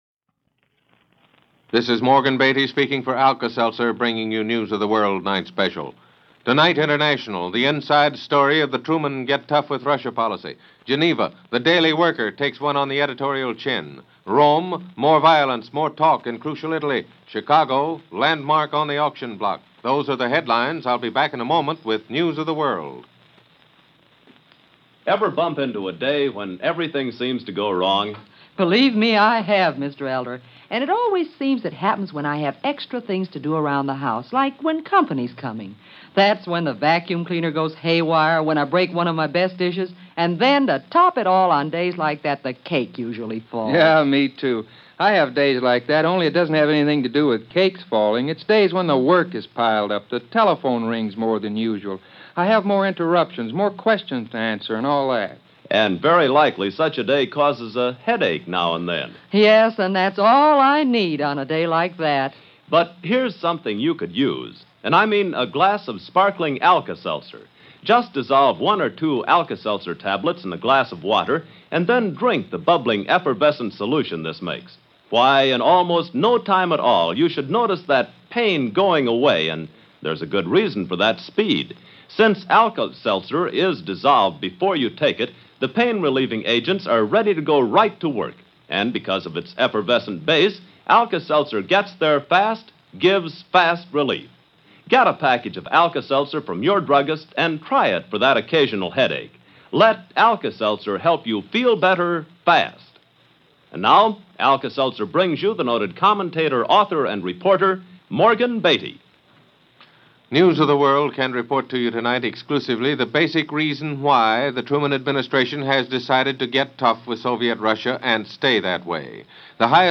News for this day in the world from NBC Radio.